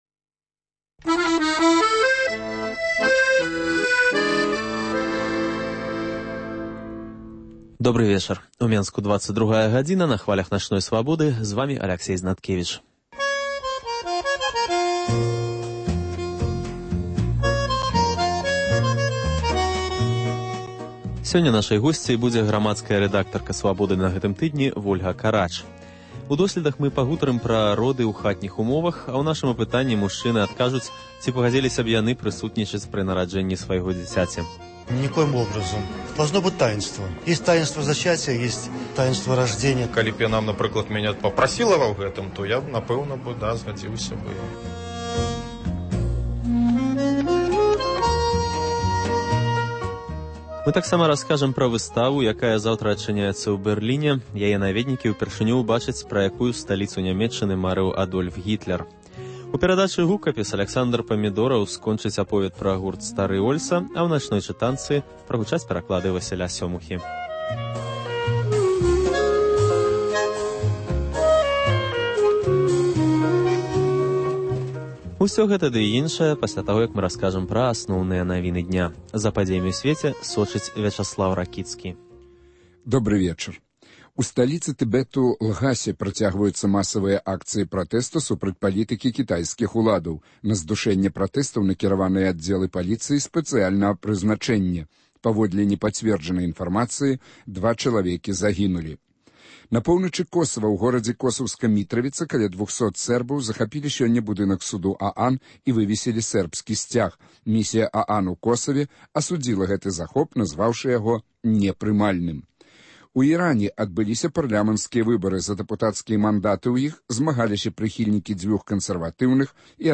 * Апытаньне сярод мужчын: ці пагадзіліся б вы прысутнічаць пры нараджэньні свайго дзіцяці? * Рэпартаж пра выставу, якая заўтра адчыняецца ў Бэрліне.